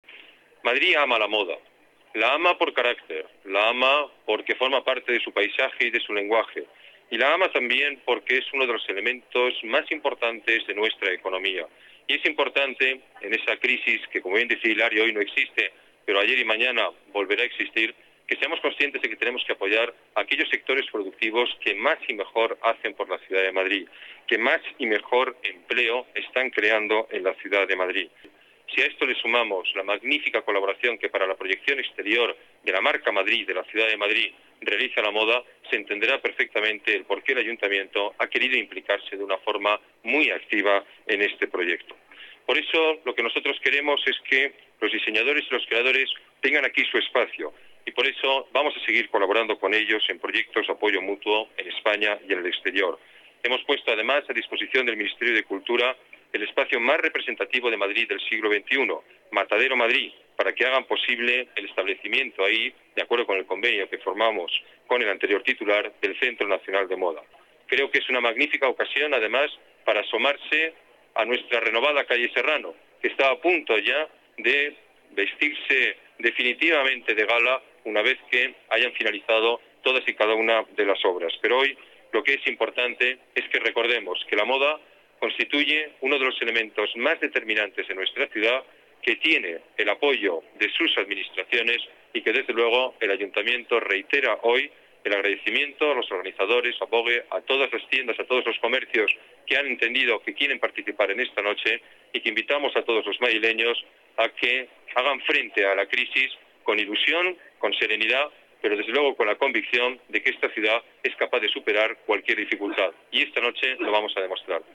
Nueva ventana:Declaraciones alcalde Noche Moda